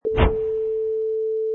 Fast whoosh, called a wipe, for video editing, movements, logos, flash, or animation
Product Info: 48k 24bit Stereo
Category: Sound Design / Wipes
Relevant for: whoosh, sting, stinger, design, video, editing, flame, wipe.
Try preview above (pink tone added for copyright).
Wipe_5_Long_Tail.mp3